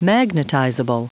Prononciation du mot magnetizable en anglais (fichier audio)
Prononciation du mot : magnetizable